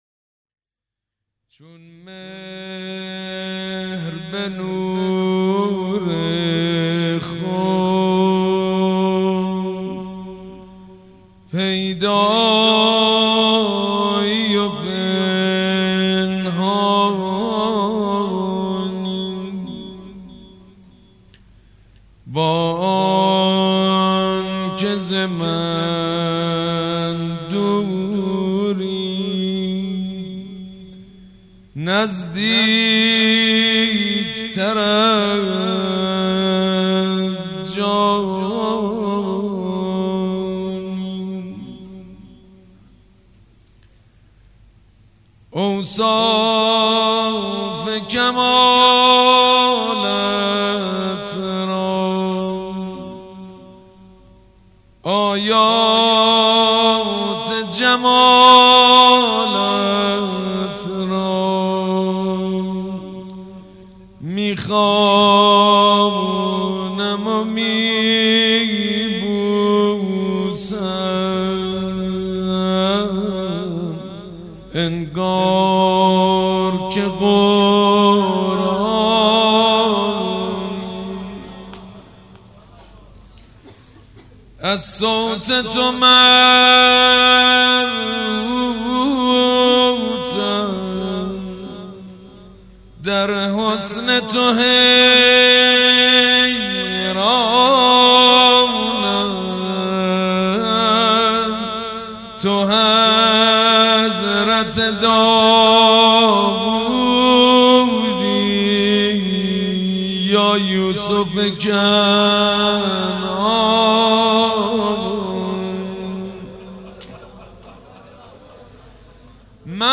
شب شهادت امام صادق(ع) ۹۷
روضه